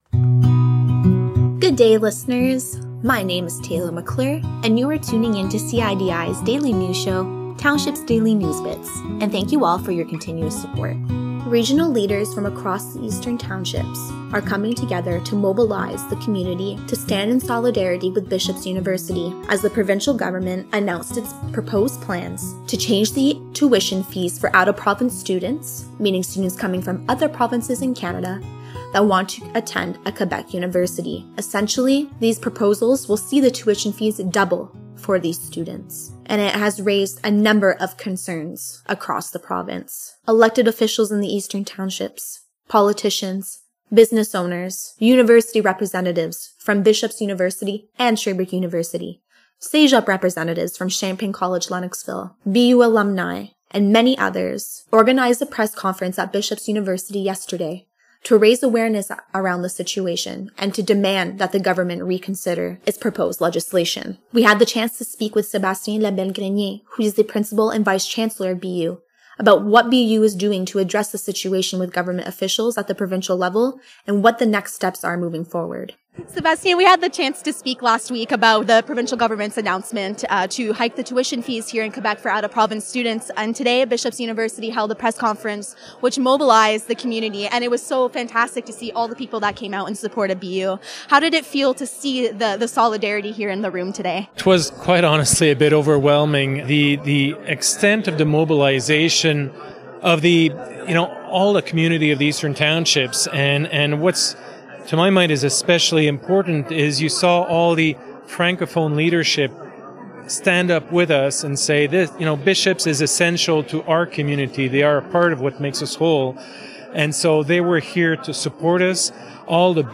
A press conference was held at BU’s Centennial Theatre on Oct. 31 that saw over 187 community leaders, including former politicians, elected officials, business owners, university representatives, and BU alumni, stand behind the university and demand that government officials reconsider their proposed university tuition fee policies.
Press-Conference-at-BU.mp3